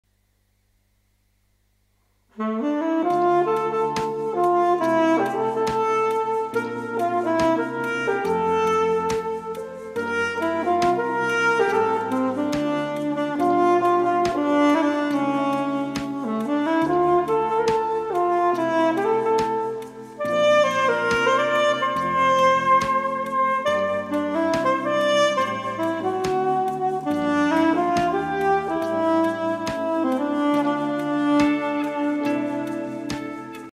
Saxophone) – Không Lời